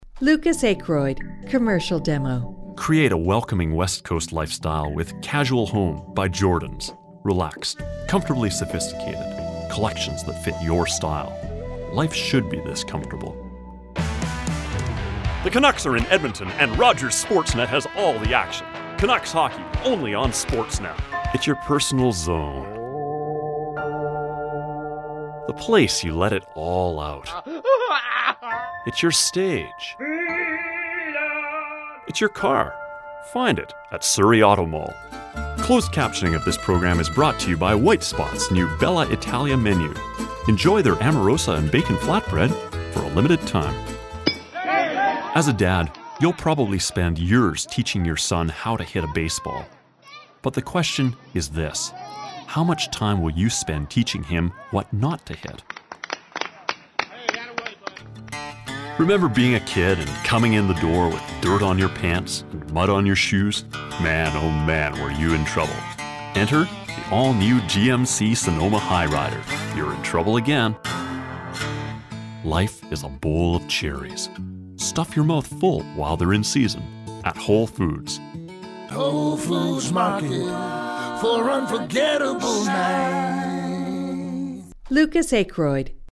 I do audiobooks, commercials, and narration.